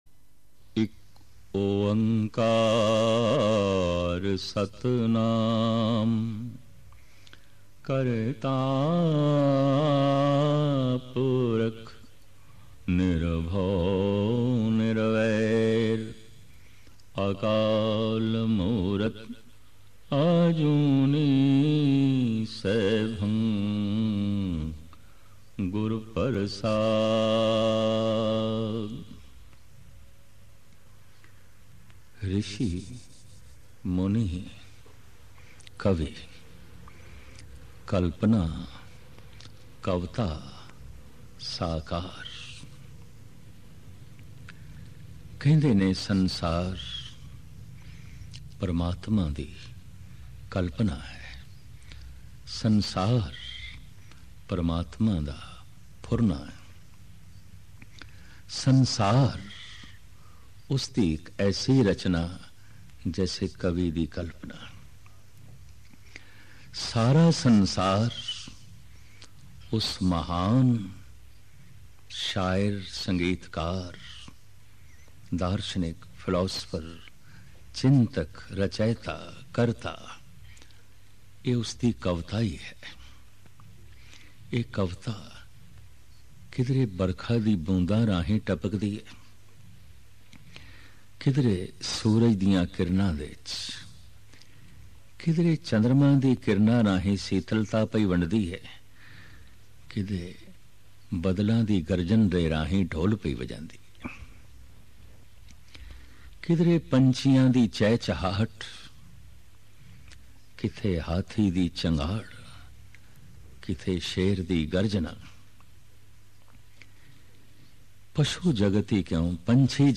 Rishi_muni_Kavi Genre: Gurmat Vichar